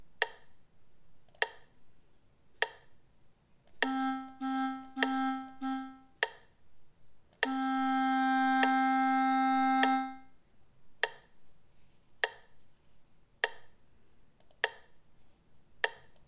A continuación escoitaredes unha serie de códigos morse coma os que acabamos de aprender, e deberemos escoller de entre as diferentes opcións que se presentan a imaxe que máis se axusta a cada código.